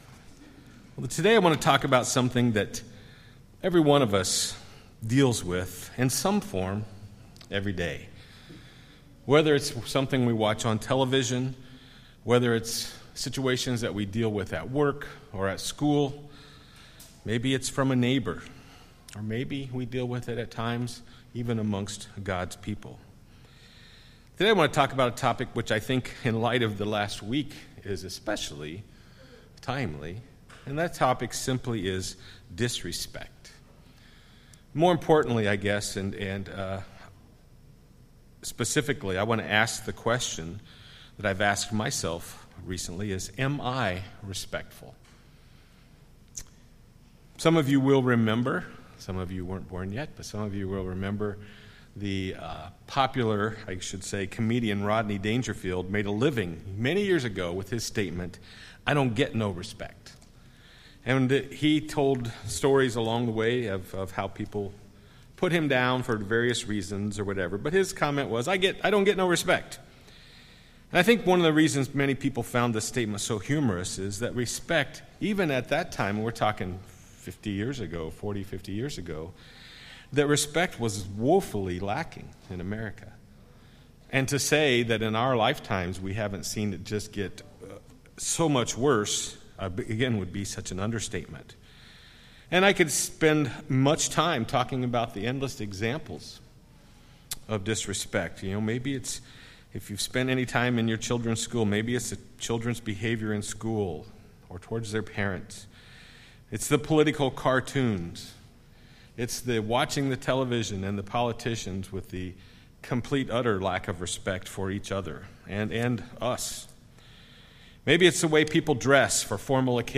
Sermons
Given in Milwaukee, WI